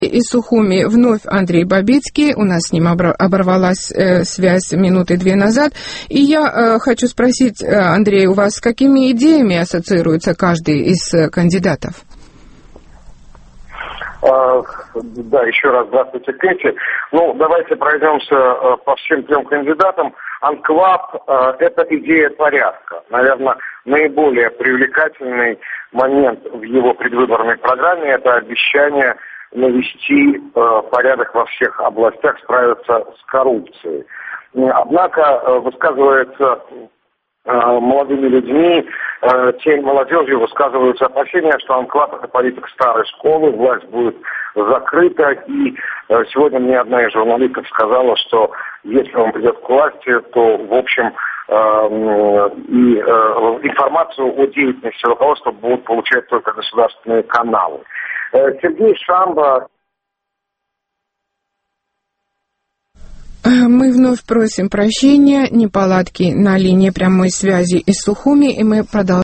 И у нас сейчас на прямой телефонной связи из Сухуми Андрей Бабицкий.